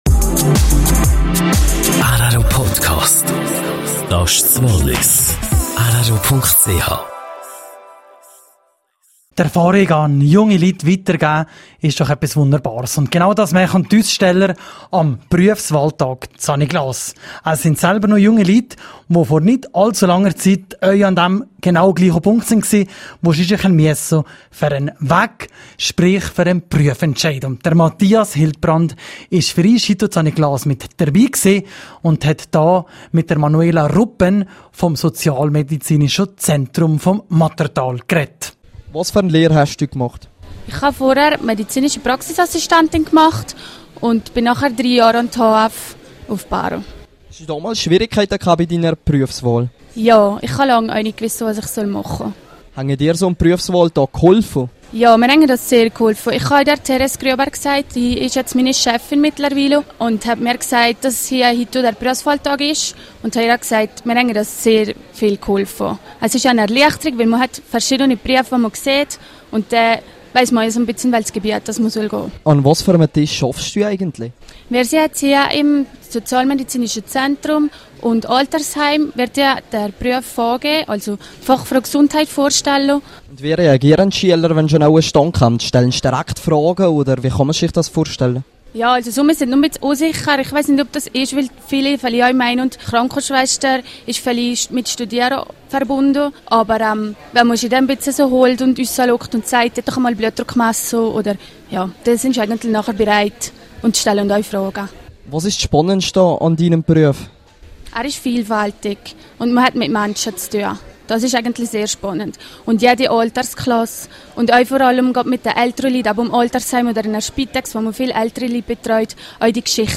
Berufswahltag St. Niklaus: Interview